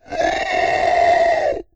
Monster_Death3.wav